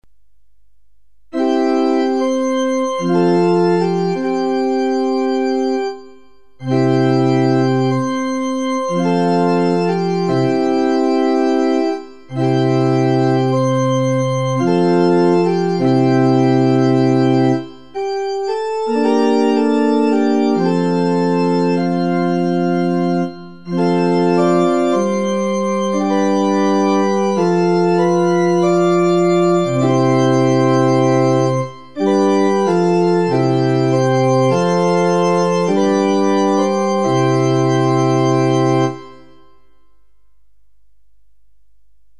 INSTRUMENTAL
Grabaciones hechas en la Ermita durante la celebración de las Novenas del año 2019/2022.